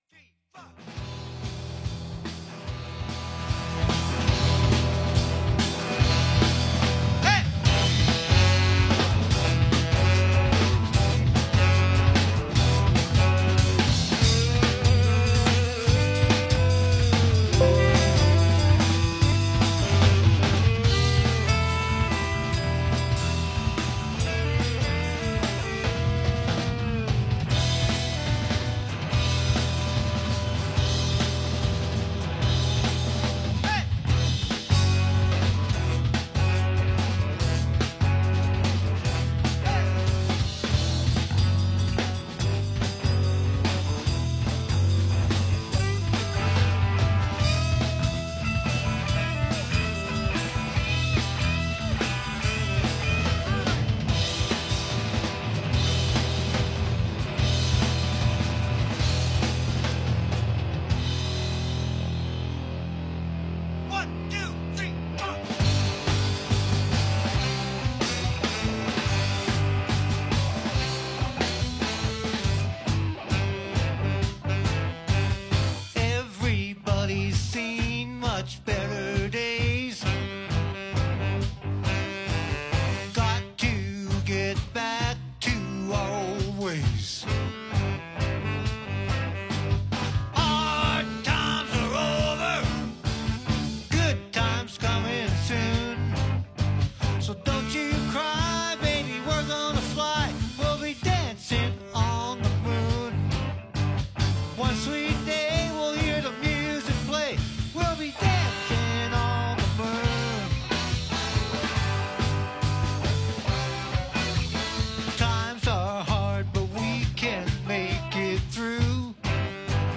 Rock 'N Roll Originals and covers